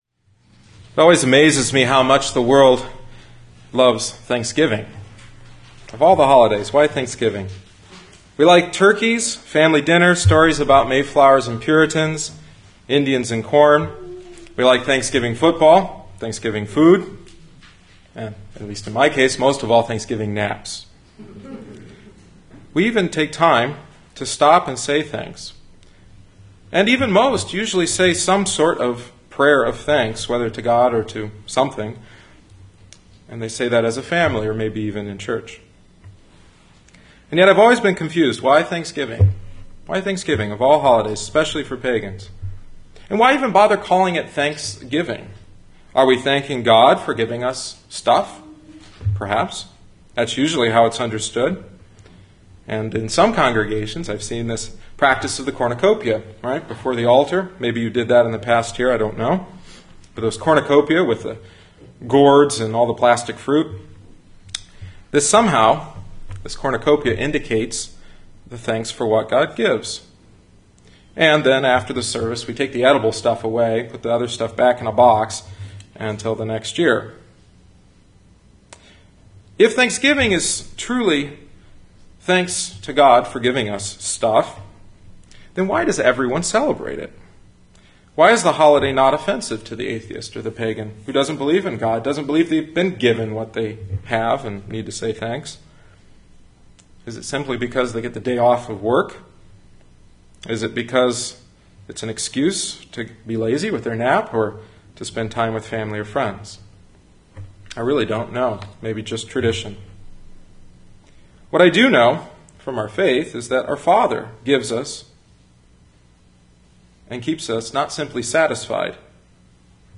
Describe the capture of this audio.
Grace Lutheran Church – Dyer, Indiana 24. November 2010 Thanksgiving Eve